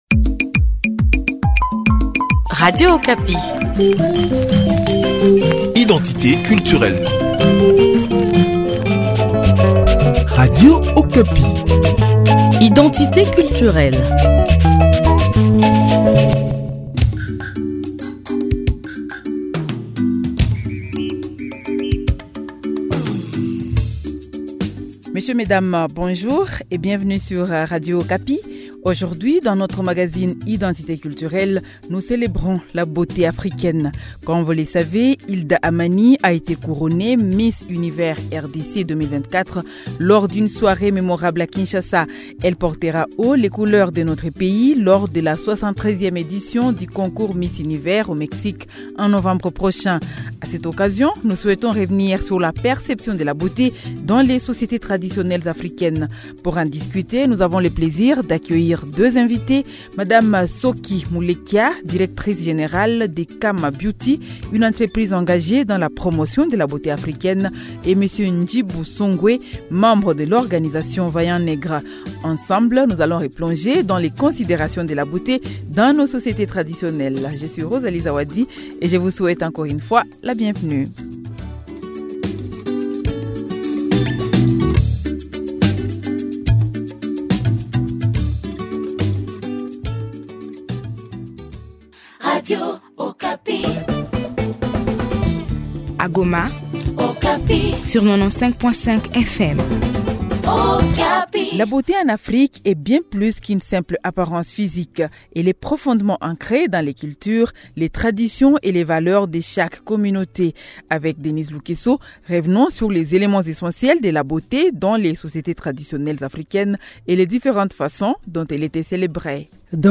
Pour en discuter, nous avons le plaisir d'accueillir deux invités